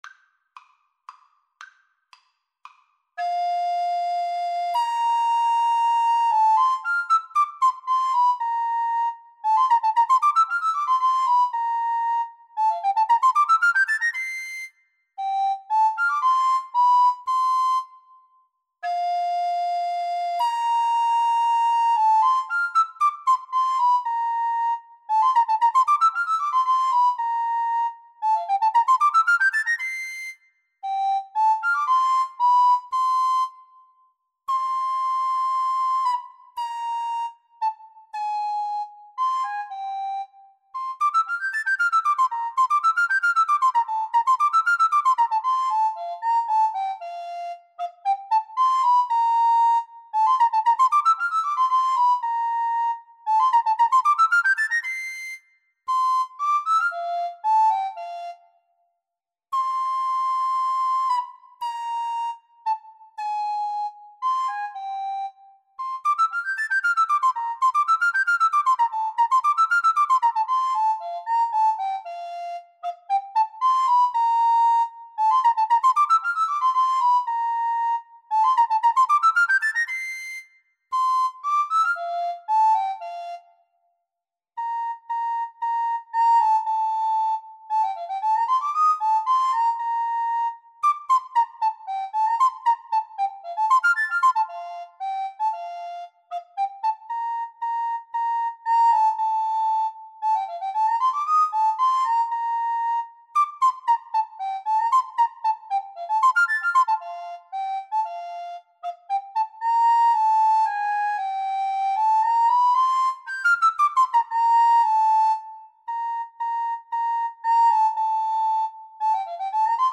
Free Sheet music for Recorder Duet
F major (Sounding Pitch) (View more F major Music for Recorder Duet )
3/4 (View more 3/4 Music)